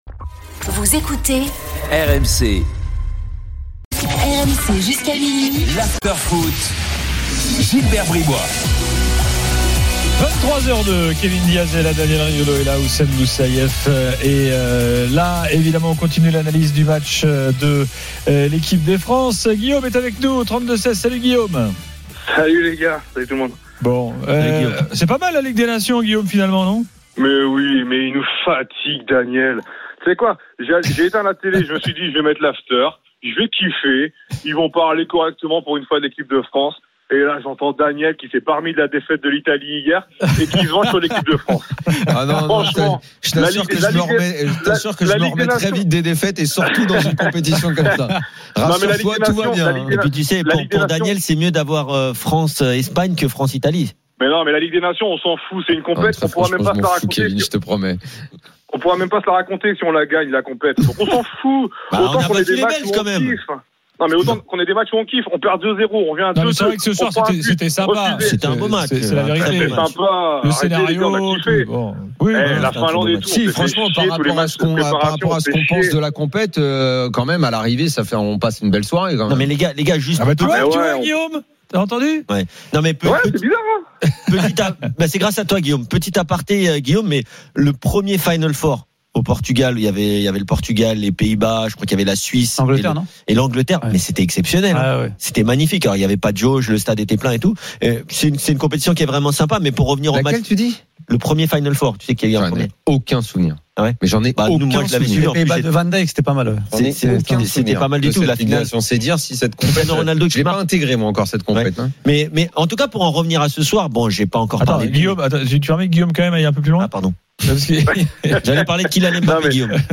Chaque jour, écoutez le Best-of de l'Afterfoot, sur RMC la radio du Sport !